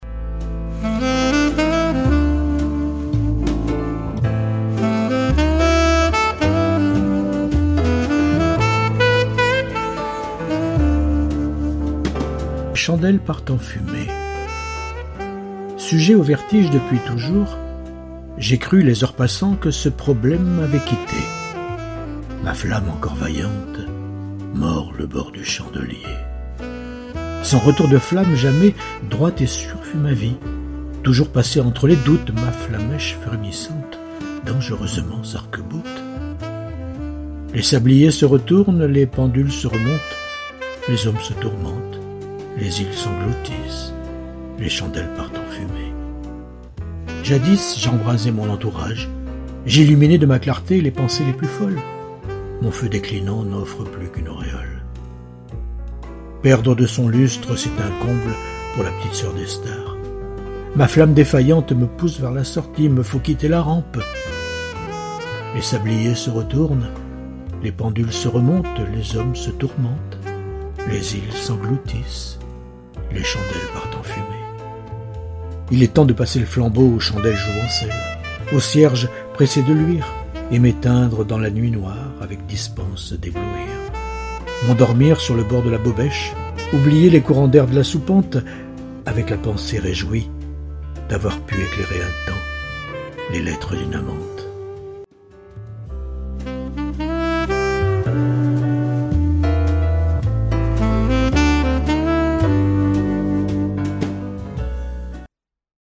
En écoute : Les chandelles partent en fumée (mise en voix par l’auteur)